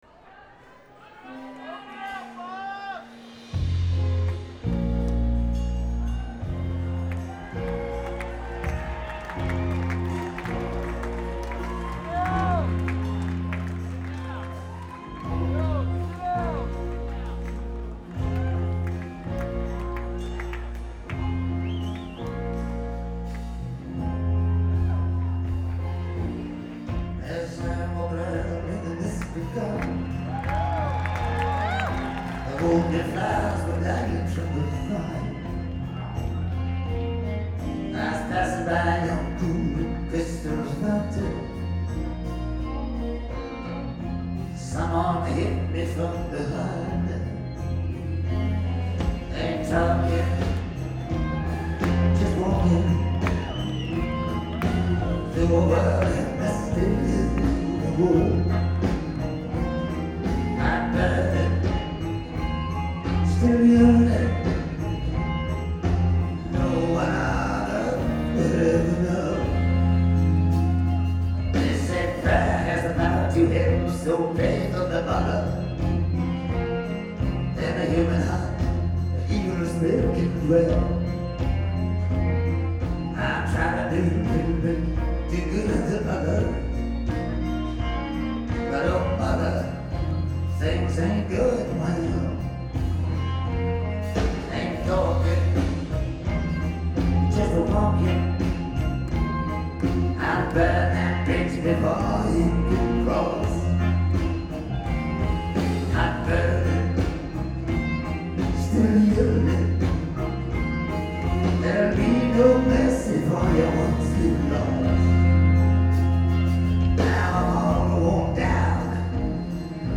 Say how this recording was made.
NEW YORK